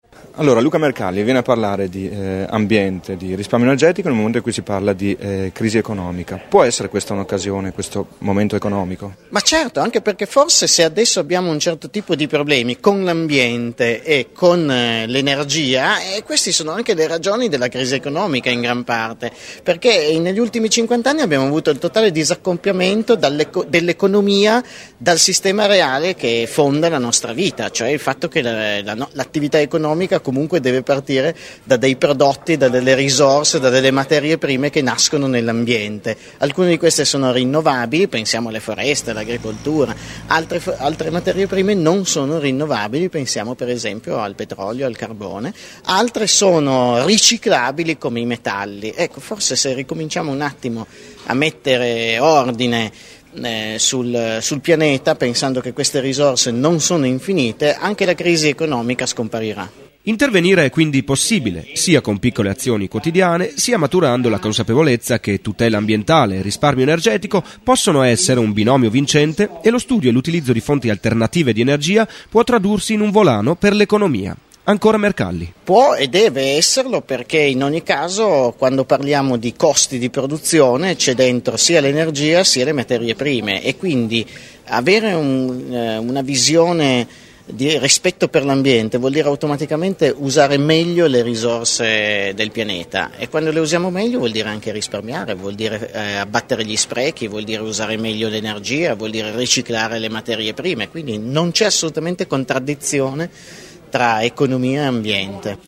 Il climatologo LUCA MERCALLI al convegno sulla nececessità di conciliare sviluppo e salvaguardia del pianeta.